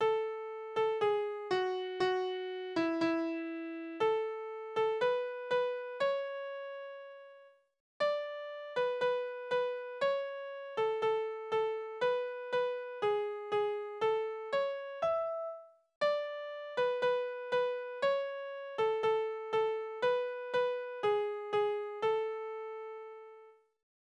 Dialoglieder
Tonart: A-Dur
Taktart: 4/4
Tonumfang: kleine Sexte